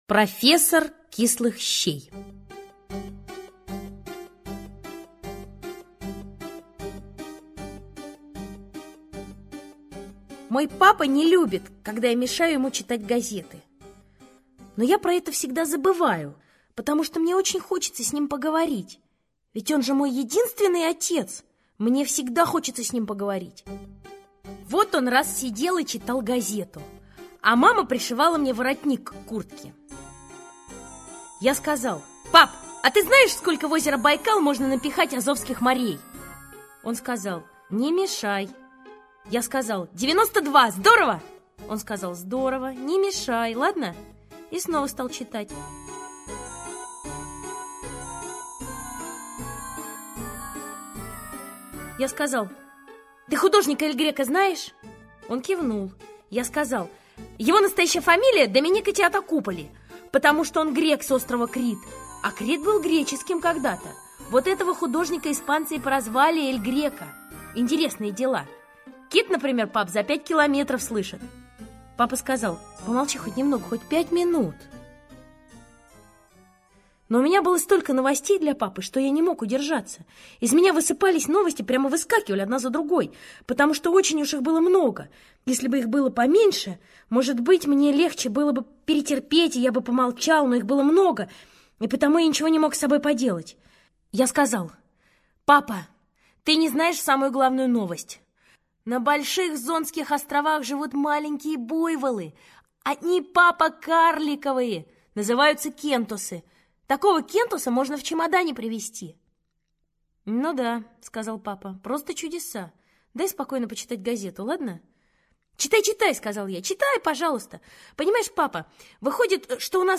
Аудиорассказ «Профессор кислых щей»